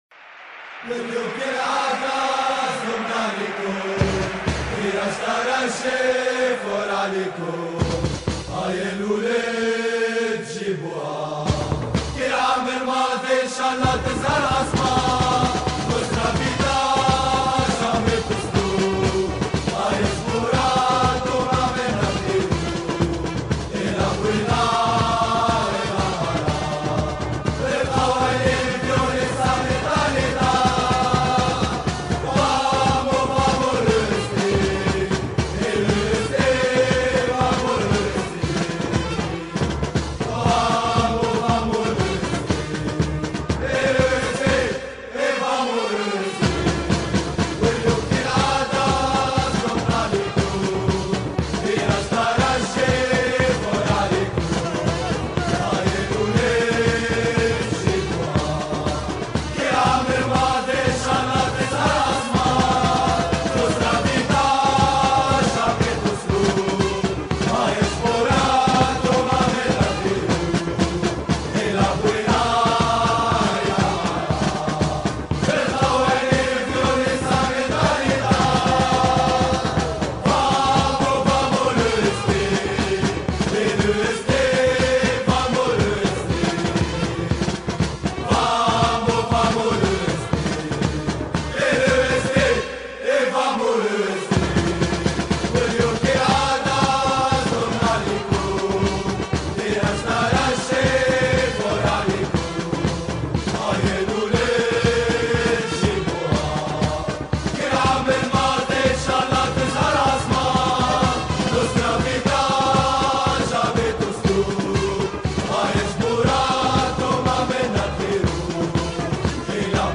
4000 exemples de spots radio